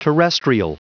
Prononciation du mot terrestrial en anglais (fichier audio)
Prononciation du mot : terrestrial